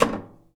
metal_tin_impacts_soft_02.wav